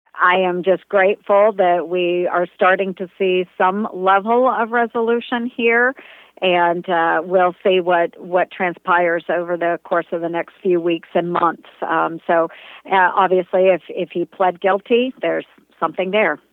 Republican Senator Joni Ernst was asked for her reaction during a conference call with Iowa reporters.